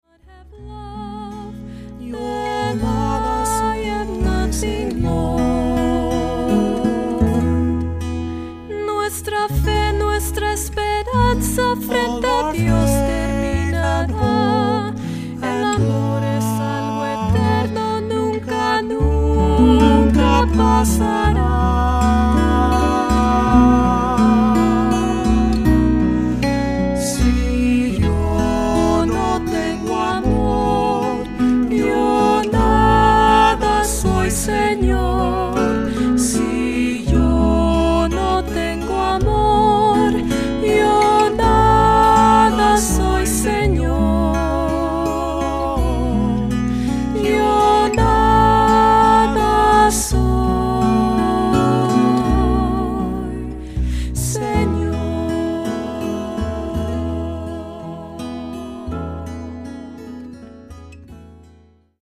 Voicing: Two-part equal; Two-part mixed; Solos; Assembly